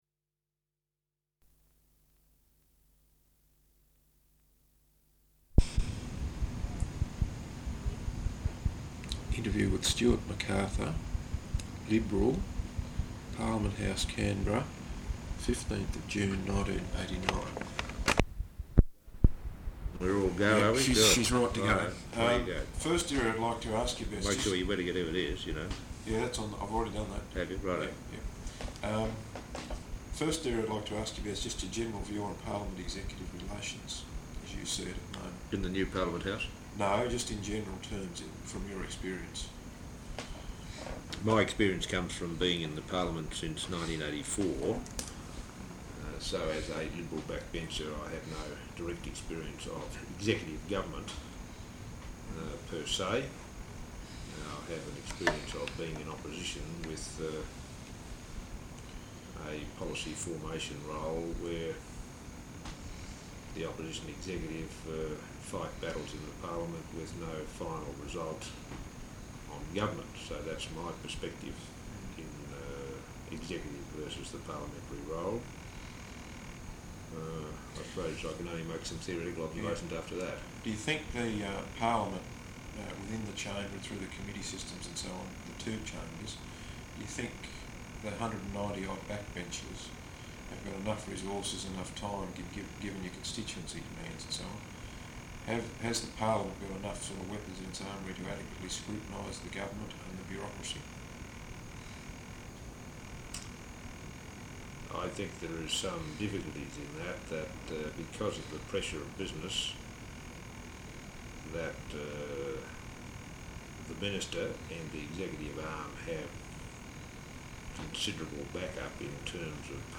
Interview with Stewart McArthur, Liberal, Parliament House, Canberra, 15th June 1989.